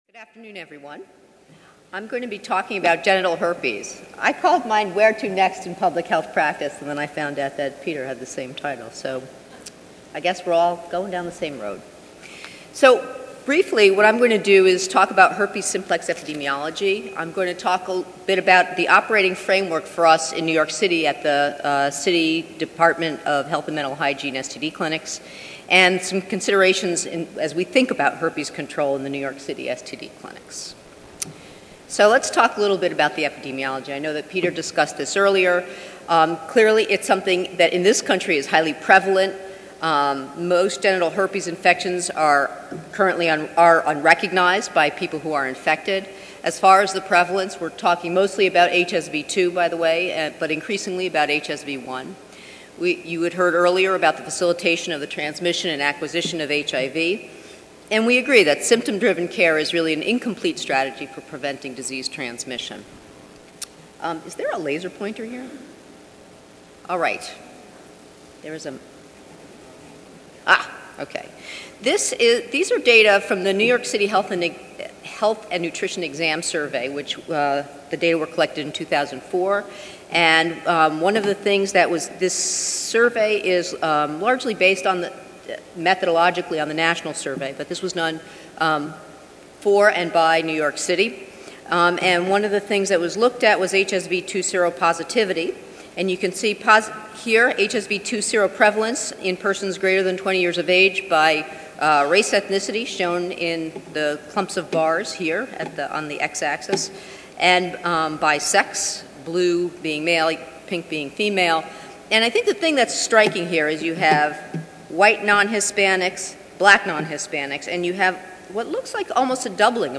International Ballroom North
Recorded presentation